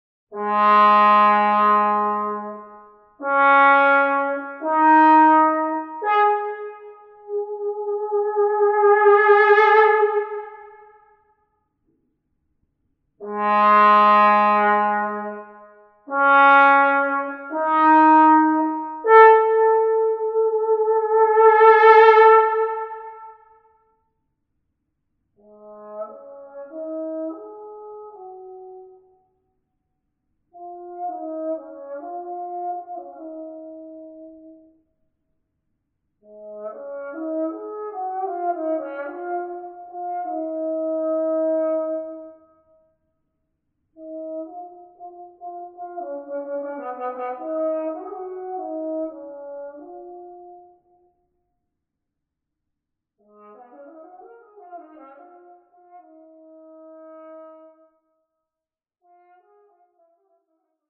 Gattung: Posaune Solo
Besetzung: Instrumentalnoten für Posaune